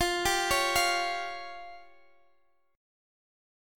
Fsus2#5 chord